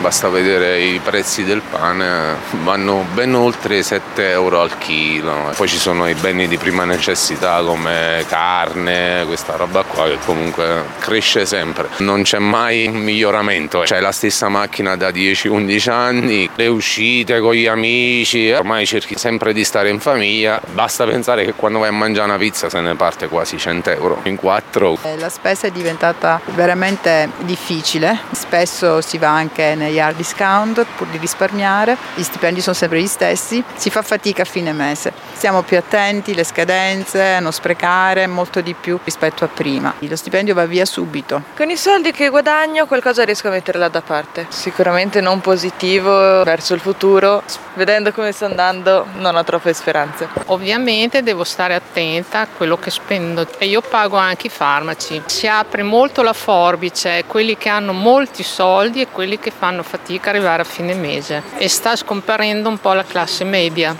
Qui sotto alcune interviste che raccontano le difficoltà del momento
VOX-AUMENTO-PREZZI.mp3